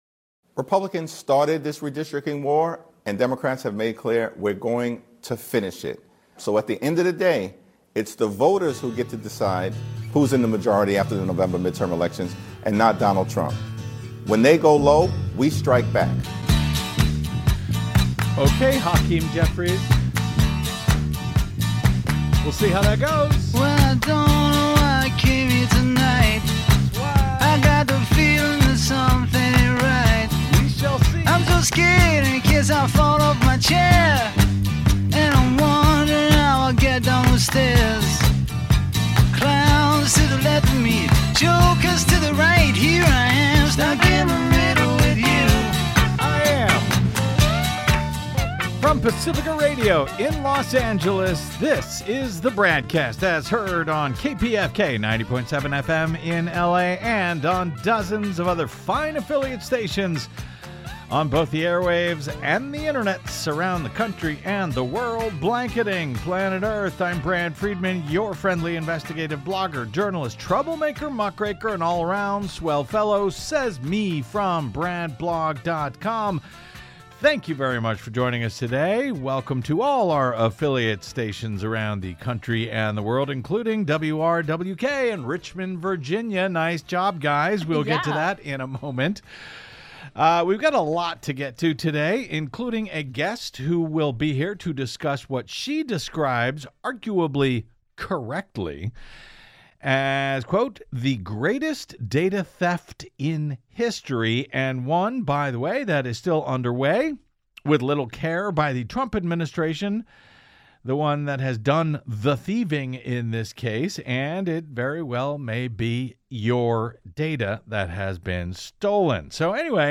We're back! Catching up with the week that was; Admin nearing contempt charges; Conservatives now warning of Trump tyranny; Callers ring in...